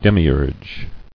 [dem·i·urge]